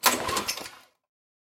描述：发动机控制单元发电机流
Tag: 单元 发电机 控制 发动机